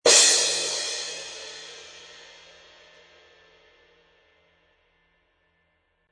The unlathed bell which contributes to the musically rich, complex texture paired with an unmistakable traditional tone. Crash Cymbals.
The Sabian 16 Artisan Crash Cymbal is fast, dark and dirty.
Sound - Dark
Loud Hit